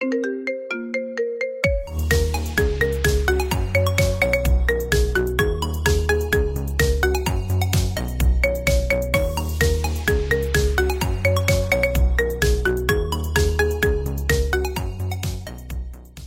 Kategorien: Marimba Remix